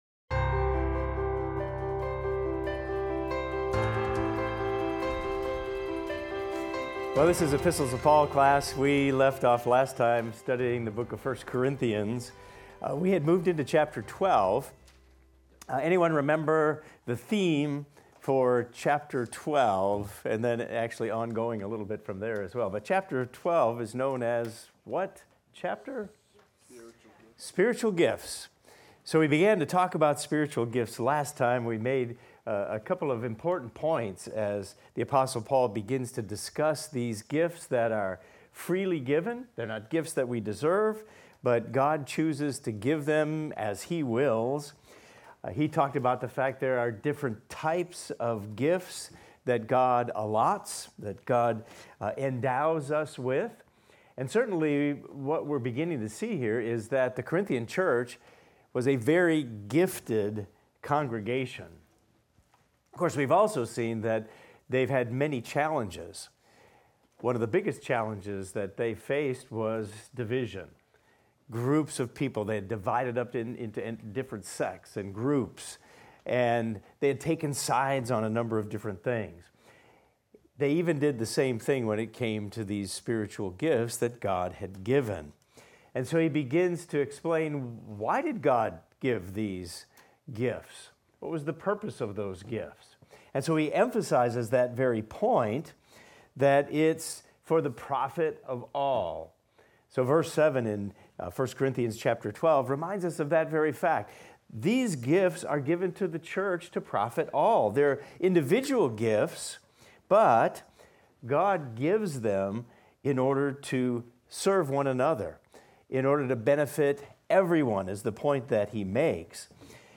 In this class we will continue our discussion of the various spiritual gifts that God gives to benefit His Church.